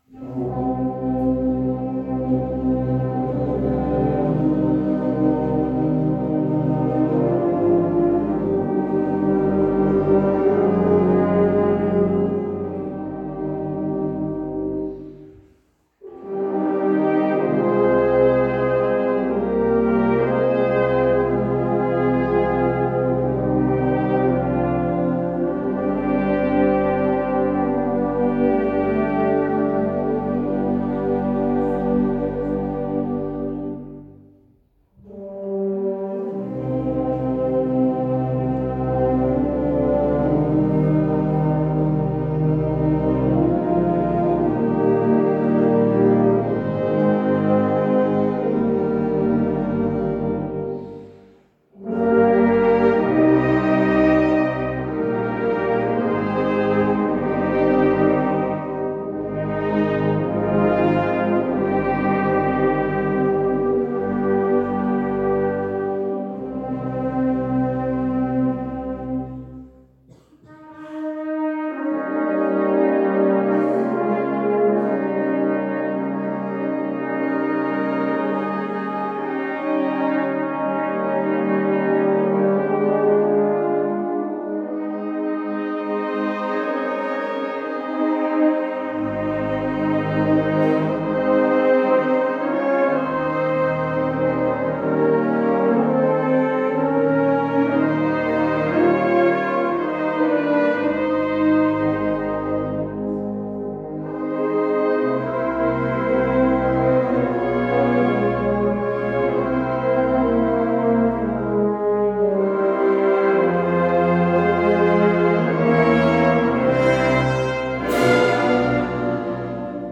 Mit dem Requiem von Julius Fucik erfolgte am Allerseelen-Tag 2019 ein äußerst würdiger Jahresabschluss.
Adagio religioso